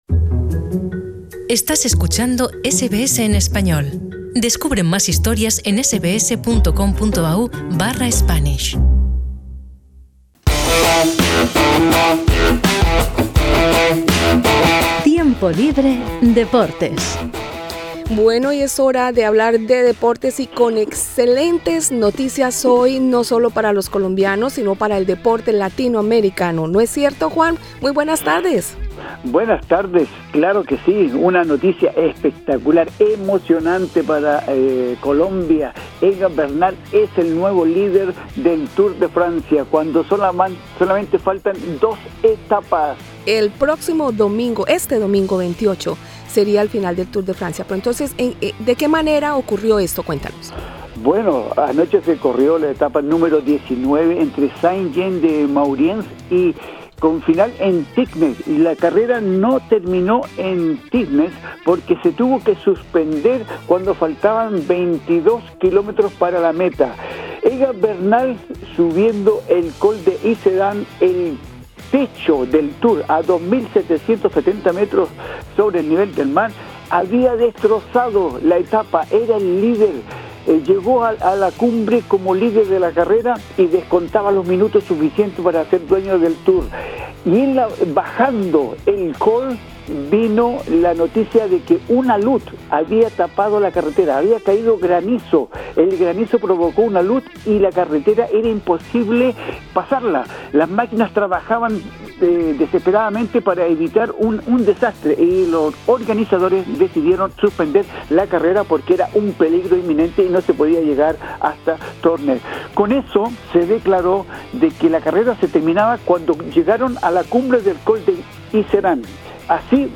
en el resúmen deportivo de éste sábado 27 de Julio